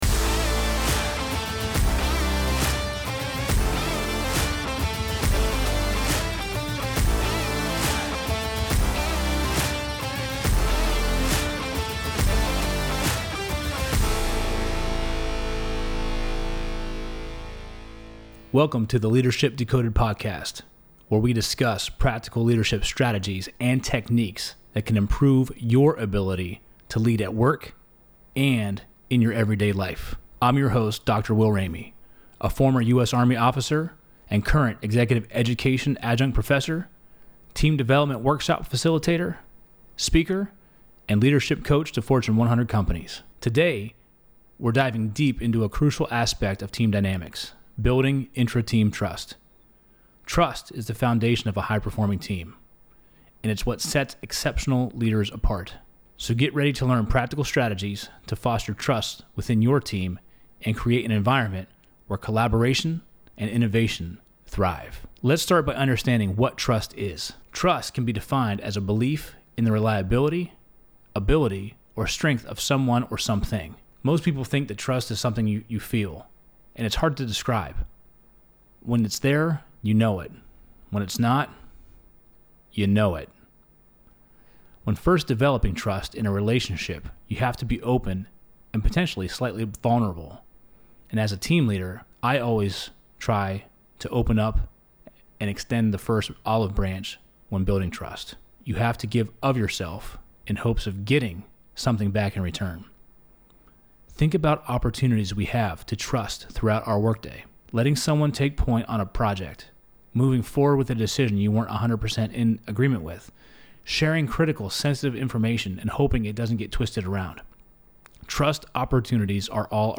Welcome to Ep.004 of the Leadership Decoded Podcast in the Loop Internet studio